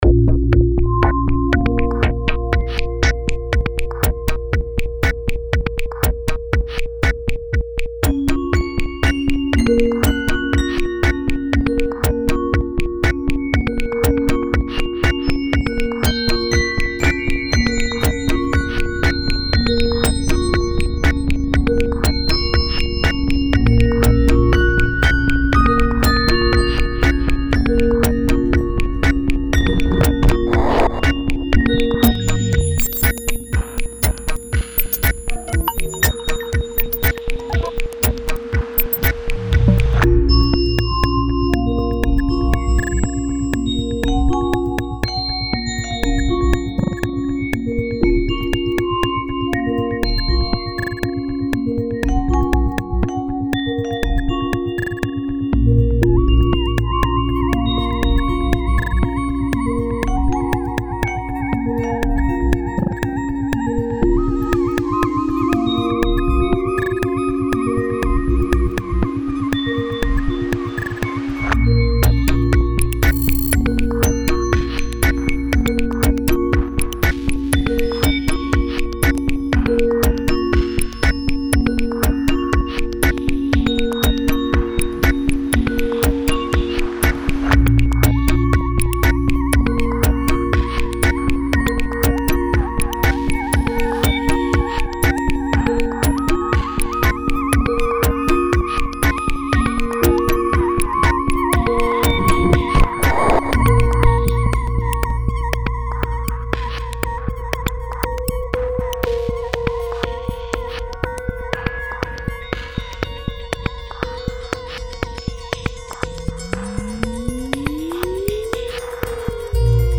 • Accompagnement sonore sur scène.
Corpuscules,  version instrumental pour cordes de piano, cithare, gong ageng et électronique (03:02)